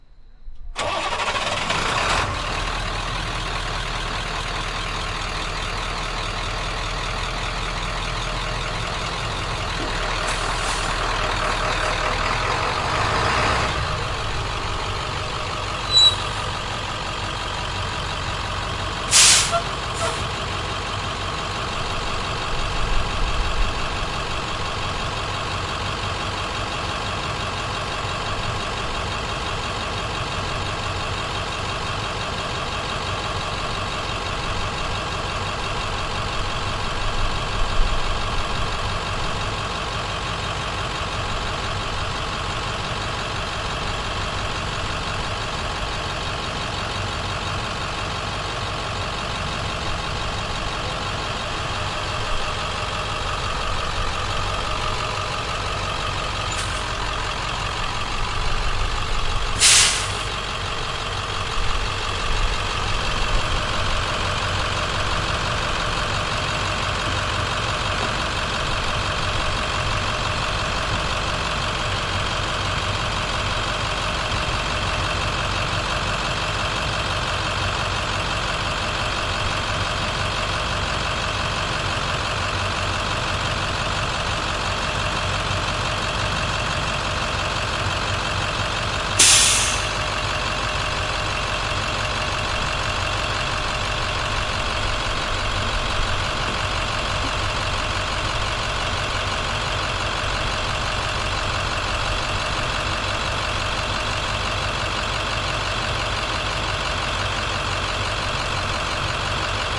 努纳维克 " 卡车皮卡经过砂石路面+交通和通风的声音
描述：卡车皮卡通过石渣路+交通和通风音
Tag: 卡车 砾石 皮卡